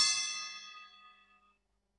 Ride Cymbal Bell
A clear ride cymbal bell hit with focused ping tone and controlled overtones
ride-cymbal-bell.mp3